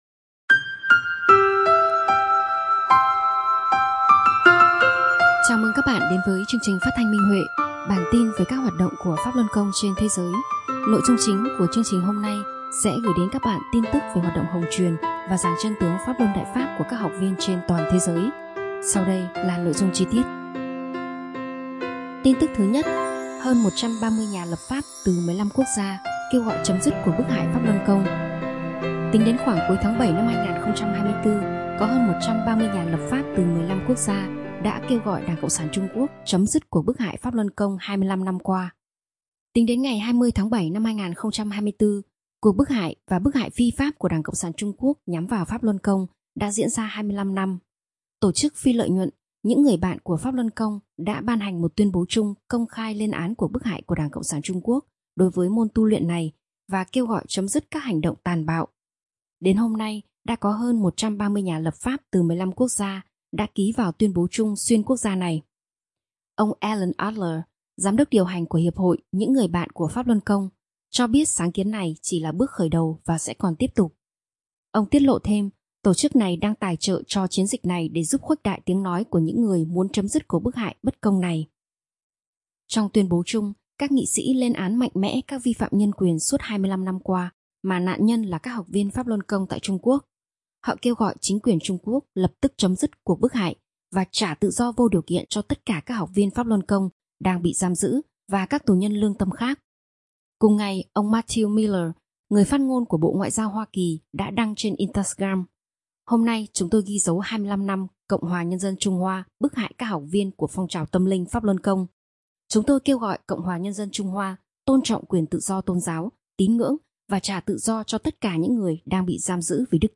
Chào mừng các bạn đến với chương trình phát thanh Minh Huệ. Chúng tôi muốn gửi đến các bạn tin tức về hoạt động giảng chân tướng và hồng truyền Đại Pháp của Pháp Luân Đại Pháp trên thế giới.